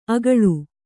♪ agaḷu